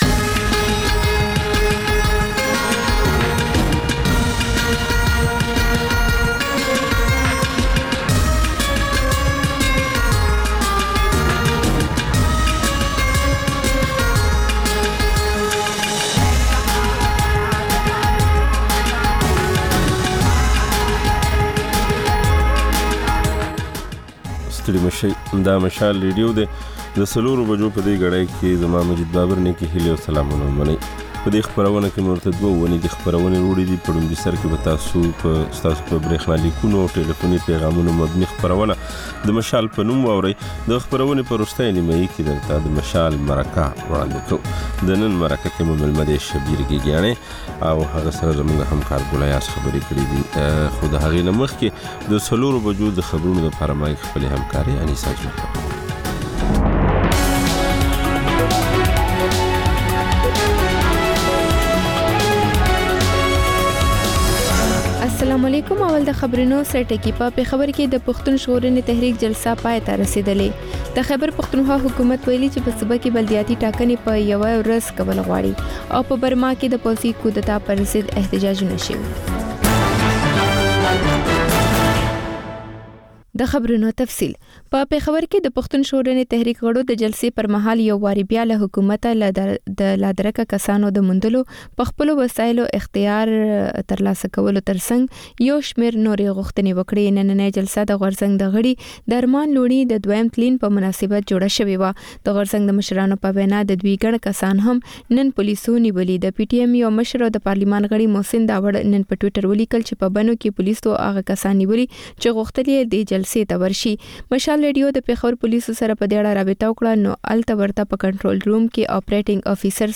د مشال راډیو مازیګرنۍ خپرونه. د خپرونې پیل له خبرونو کېږي، بیا ورپسې رپورټونه خپرېږي. ورسره اوونیزه خپرونه/خپرونې هم خپرېږي.